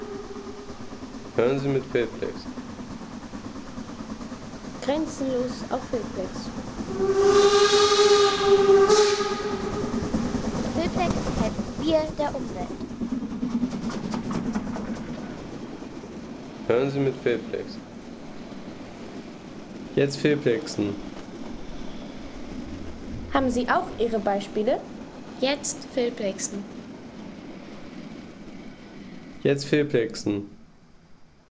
Ankunft der Dampflokomotive